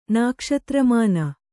♪ nākṣatra māna